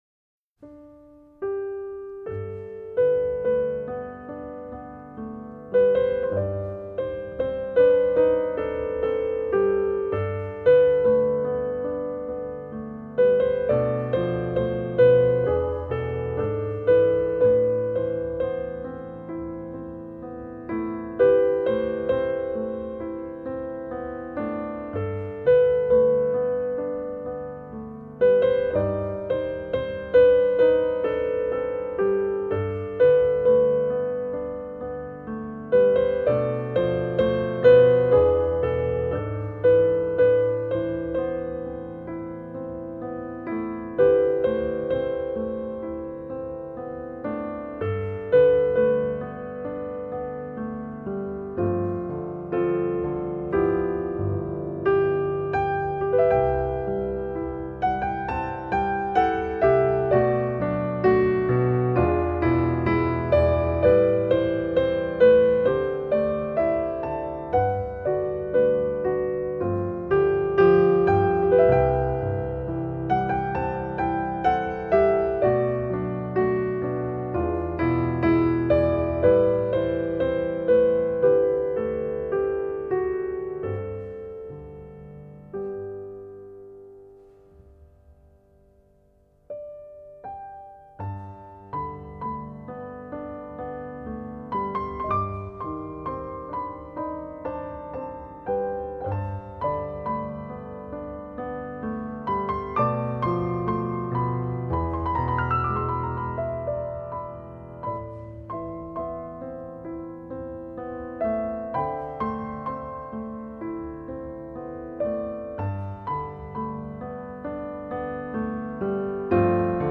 在细腻动人的旋律里，人们听到的是温暖，是柔情，也是伤感。
了兼融东方的抒情与西方的典雅细致的音乐风格。